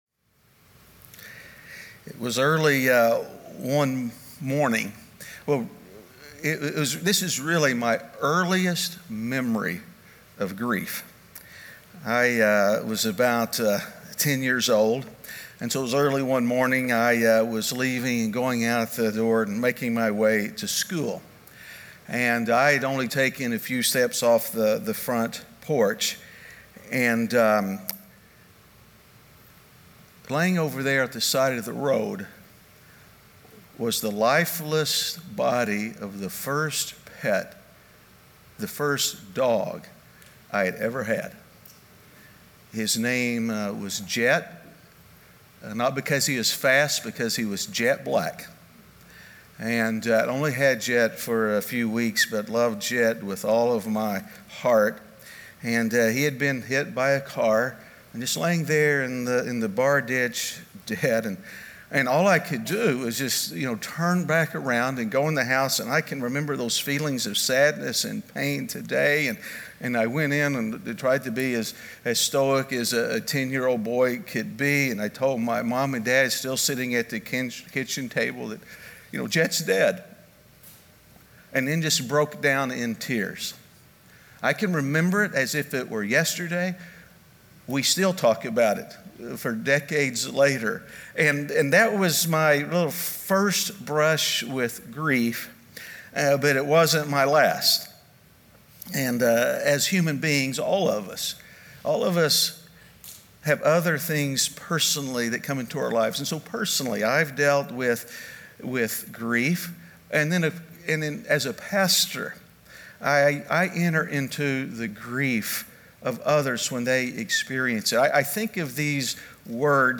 A message from the series "Good Grief."